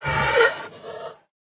Minecraft Version Minecraft Version 1.21.5 Latest Release | Latest Snapshot 1.21.5 / assets / minecraft / sounds / mob / panda / aggressive / aggressive1.ogg Compare With Compare With Latest Release | Latest Snapshot
aggressive1.ogg